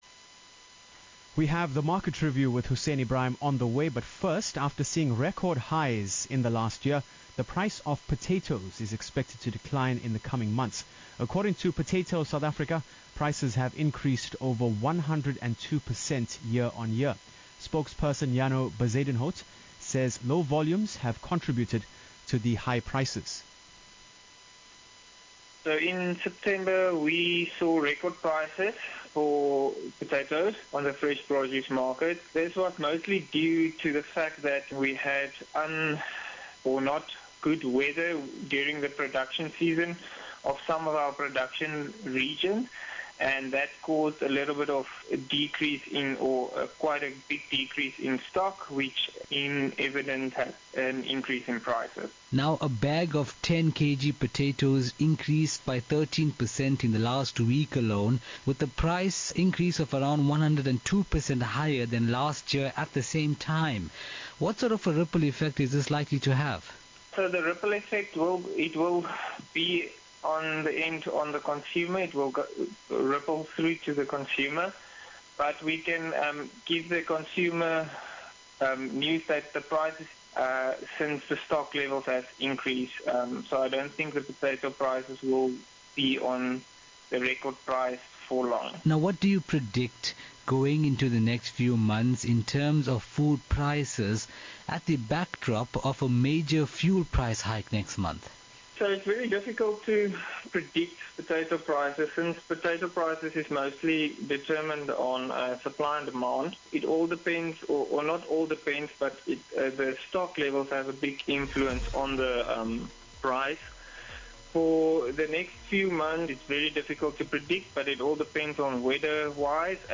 Lotus_FM_October_2021.mp3